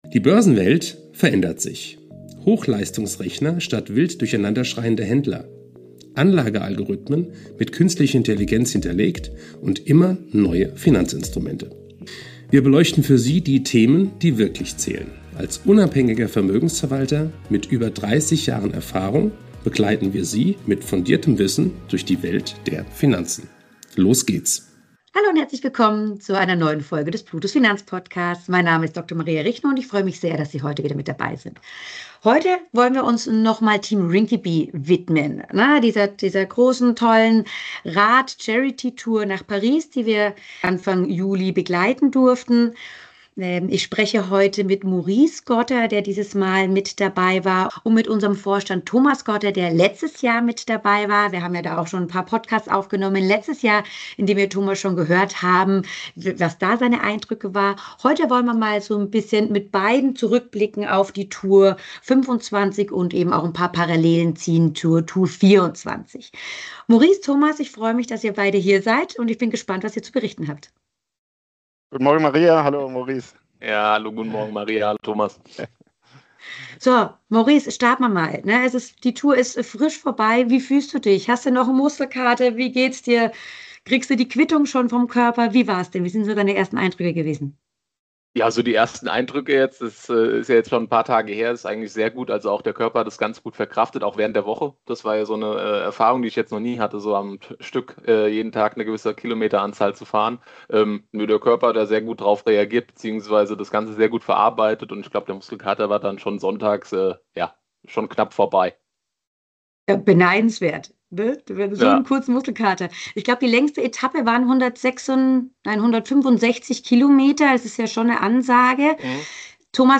im Gespräch ~ Plutos Finanzpodcast